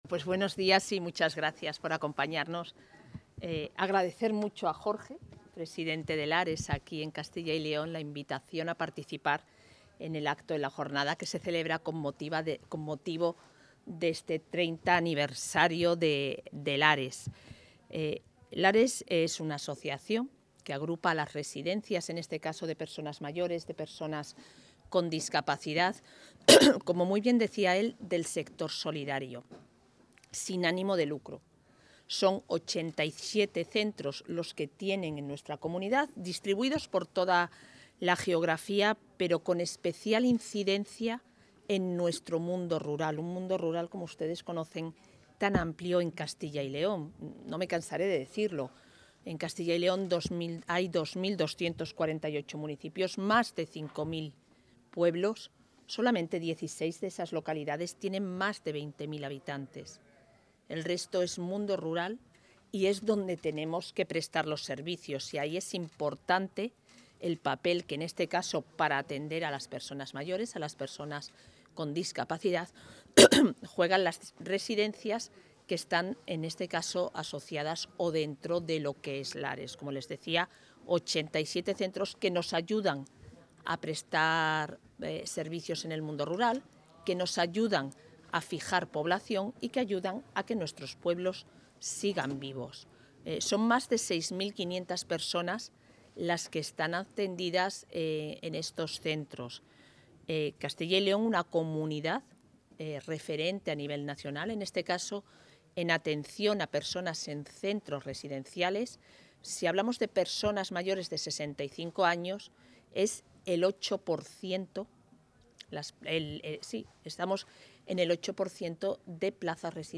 Intervención de la vicepresidenta.
La vicepresidenta y consejera de Familia e Igualdad de Oportunidades asiste a la celebración del 30º aniversario de la entidad Lares, que agrupa residencias sin ánimo de lucro y que ya ha formado a 800 profesionales para que se adapten al nuevo sistema de cuidados en centros.